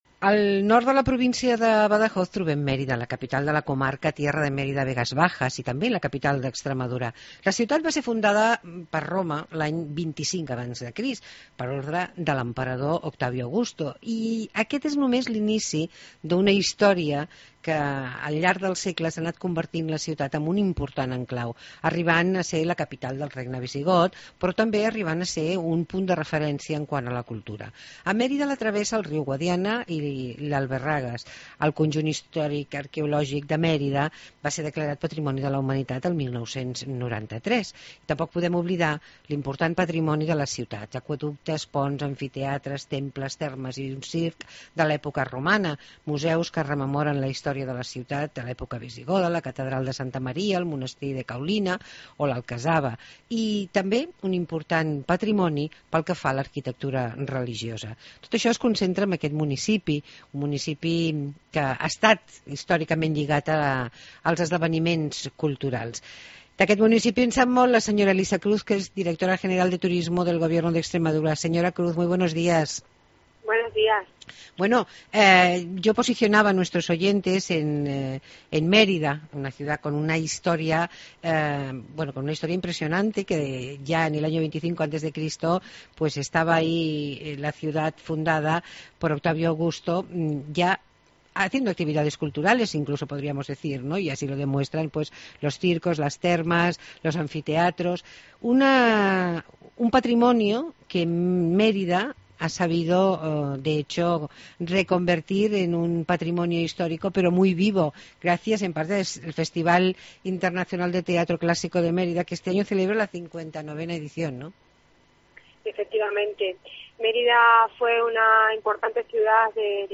Entrevista a Elisa Cruz, directora de Turismo del Gobierno de Extremadura